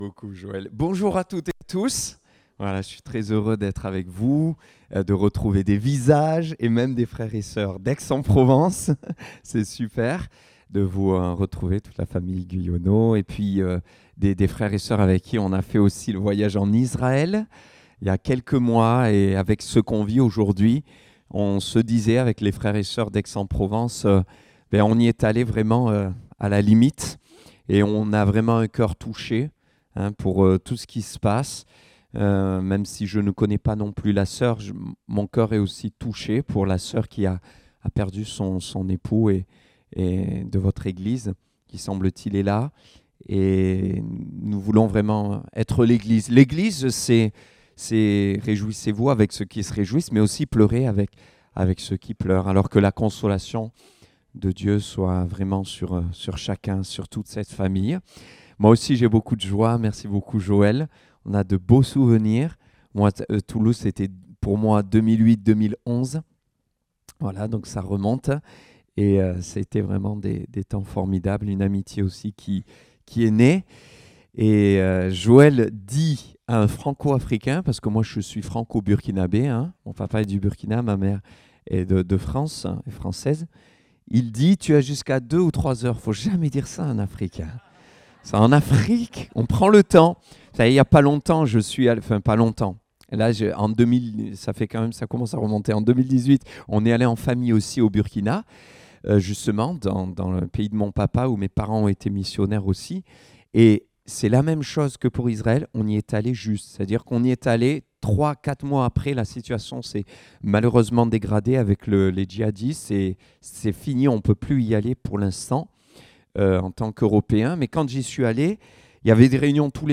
Date : 15 octobre 2023 (Culte Dominical)